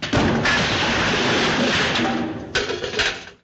BoomFall.ogg